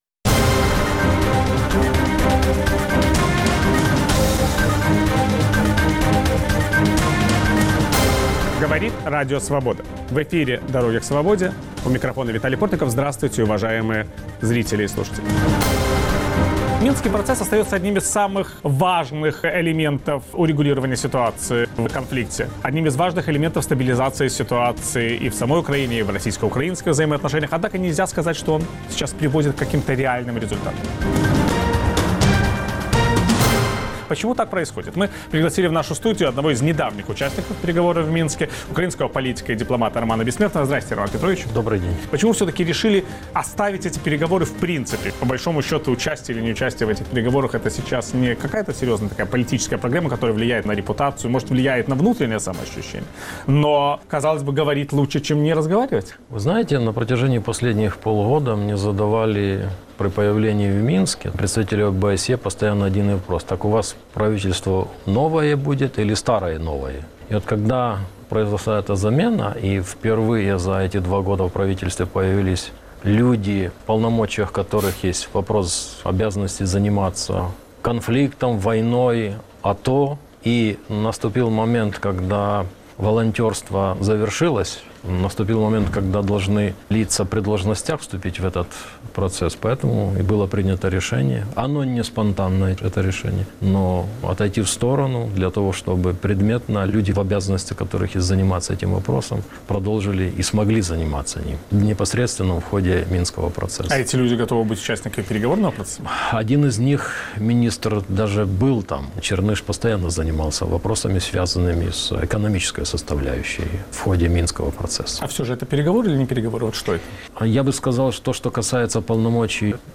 Могут ли переговоры в Минске привести к урегулированию на Донбассе? Виталий Портников беседует с украинским политиком и дипломатом, участником минских переговоров Романом Бессмертным